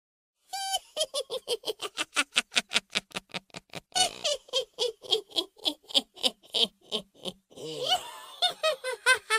SONIDO BRUJA MALVADA
Efecto de Sonido de una bruja riéndose de maldad
sonido-risa-bruja.wav